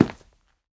scpcb-godot/SFX/Step/Step8.ogg at 59a9ac02fec0c26d3f2b1135b8e2b2ea652d5ff6
Step8.ogg